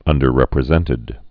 (ŭndər-rĕprĭ-zĕntĭd)